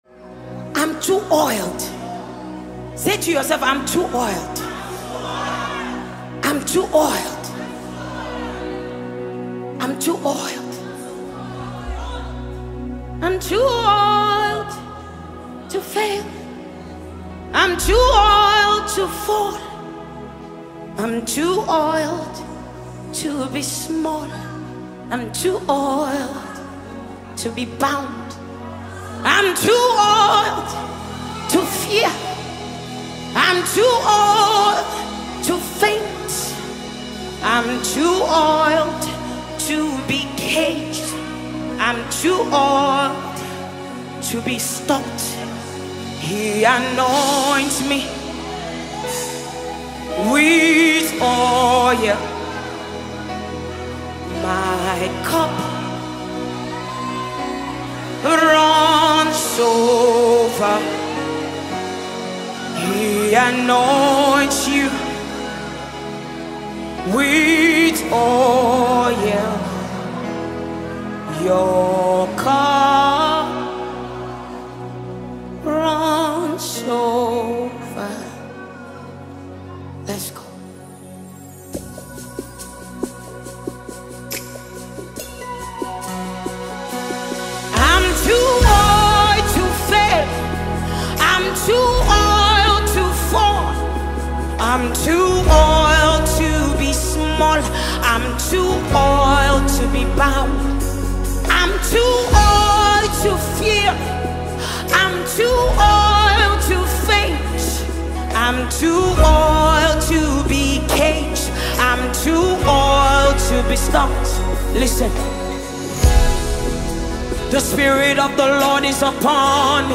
The lastest Nigerian Gospel song